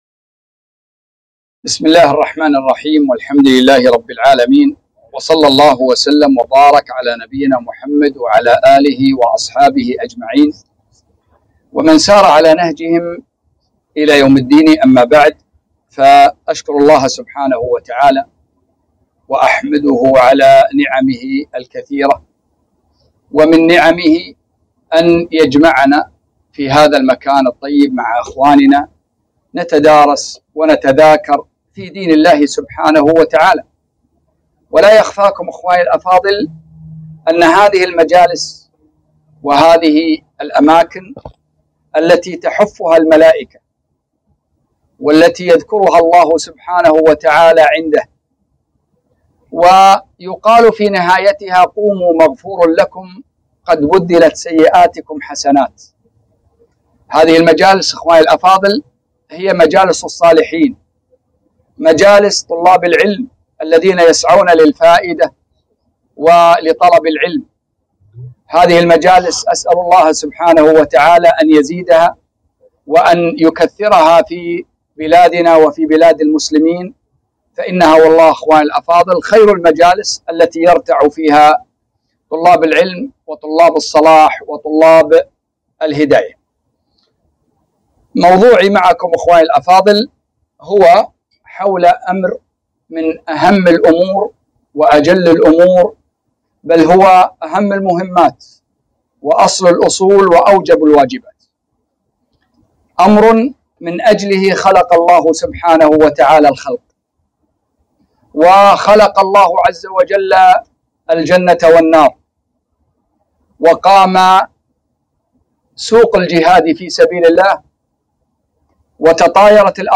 محاضرة قيمة - مكانة التوحيد